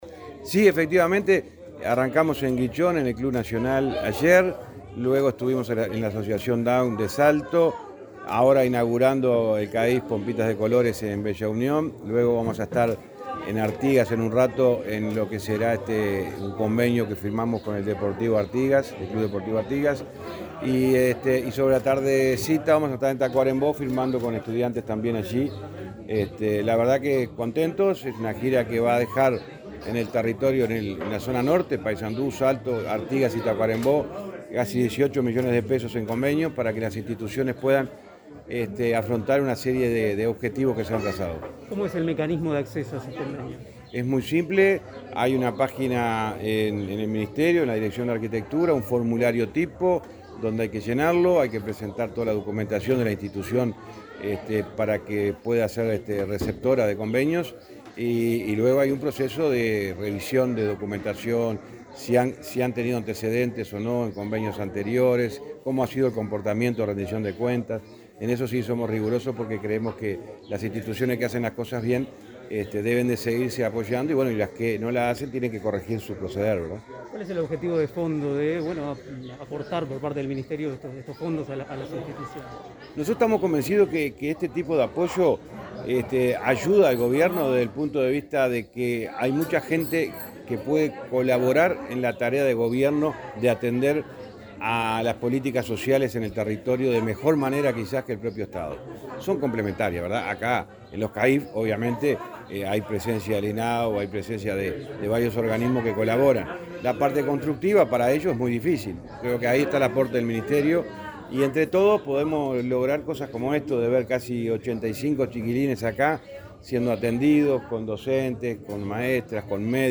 Entrevista al ministro de Transporte, José Luis Falero